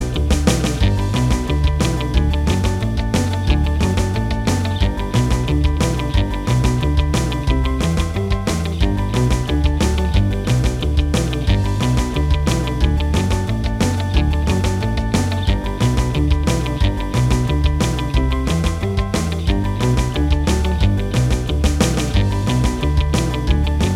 Minus Lead Guitar Pop (1960s) 2:04 Buy £1.50